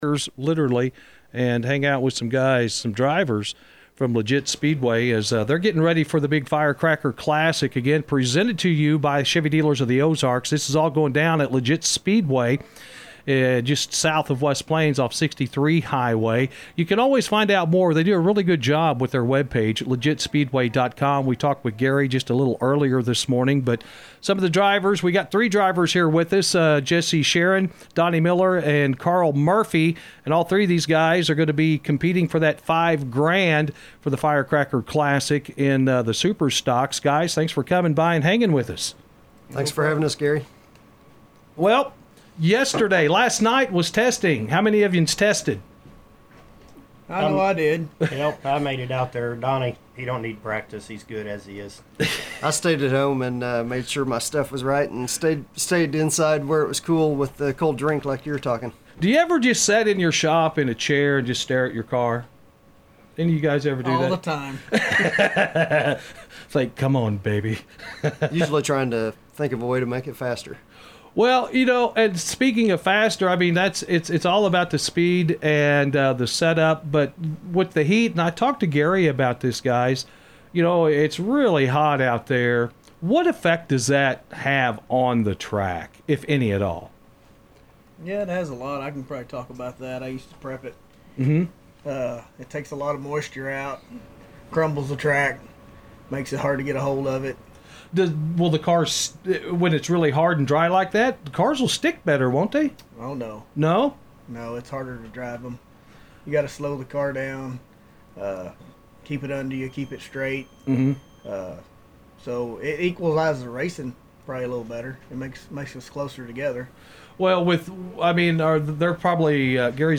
Fire Cracker Classic this weekend at Legit Speedway drivers call about this upcoming race that starts tonight